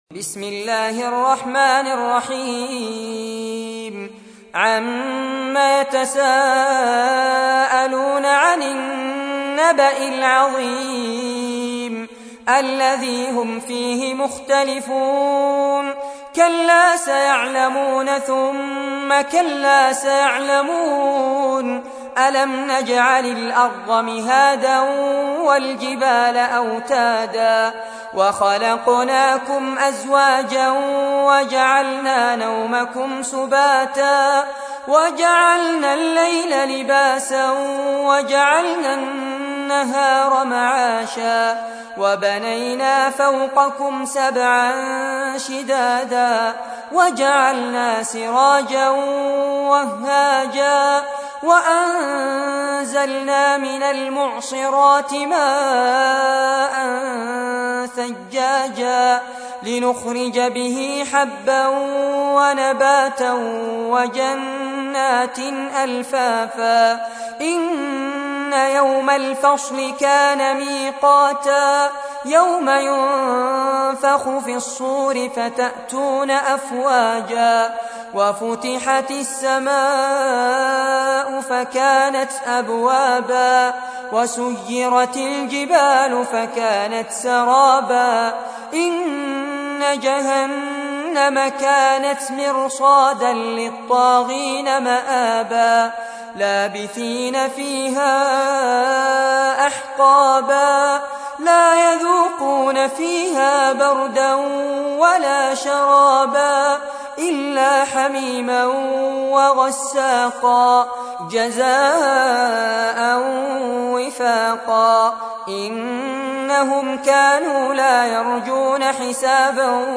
تحميل : 78. سورة النبأ / القارئ فارس عباد / القرآن الكريم / موقع يا حسين